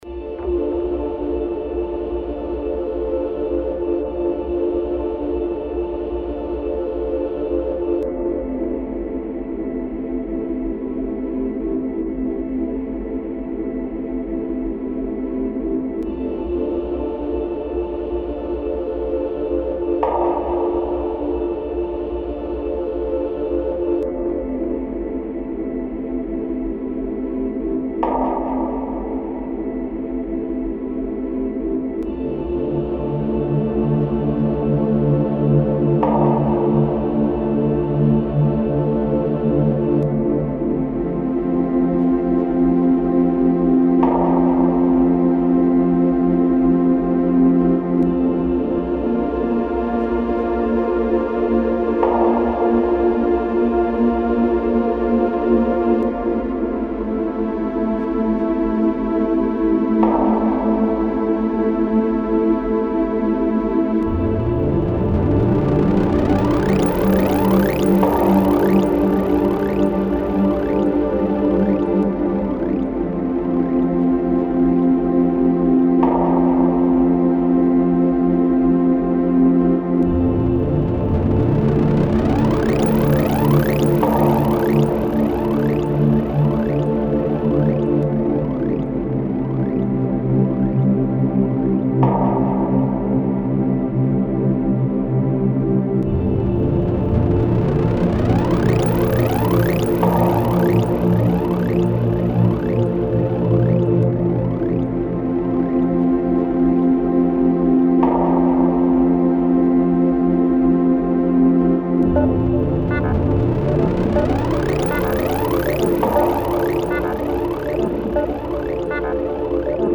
Genre Ambient